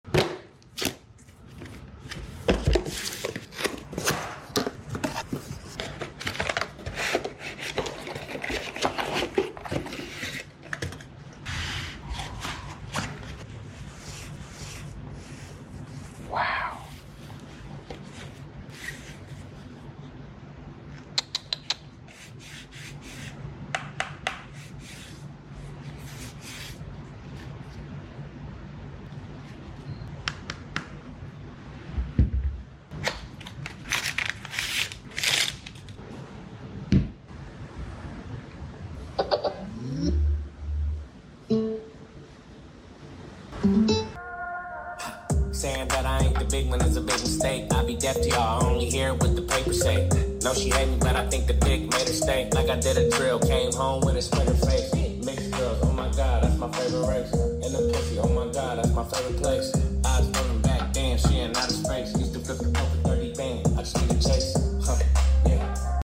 [ASMR]